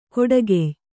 ♪ koḍagey